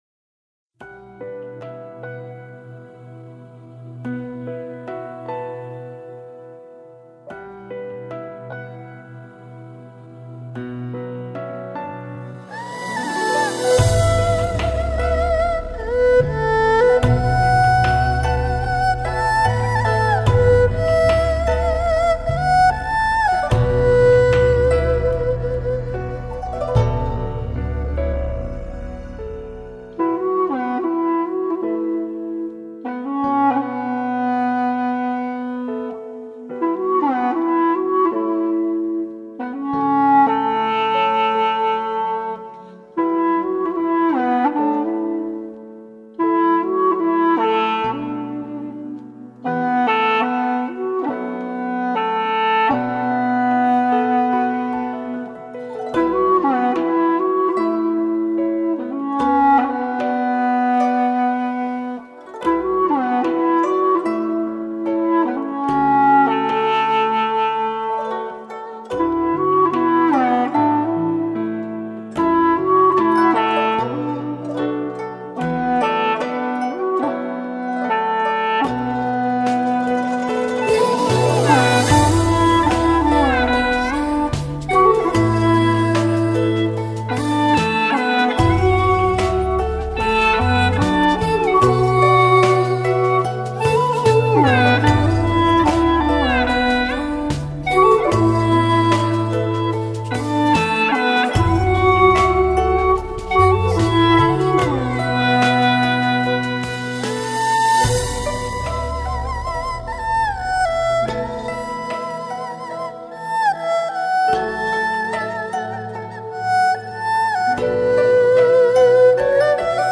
调式 : D调 曲类 : 古风